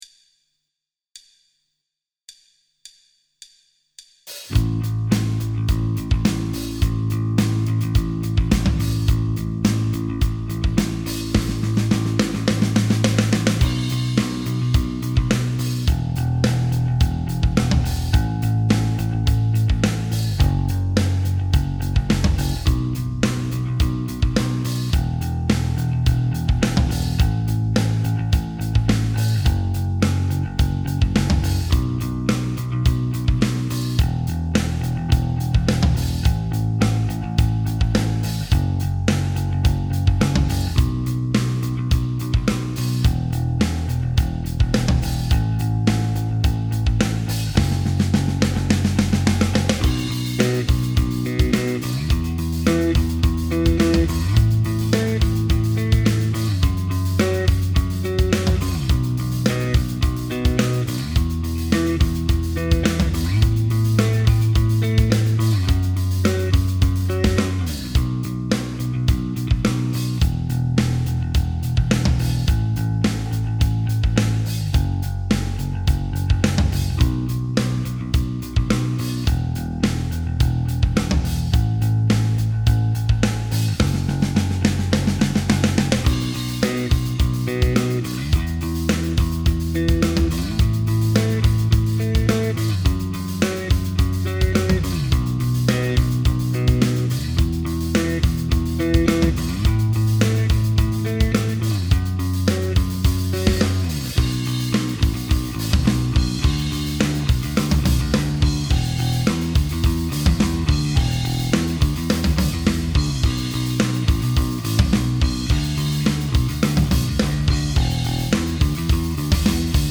BPM : 106
Tuning : E
Without vocals
Based on the album version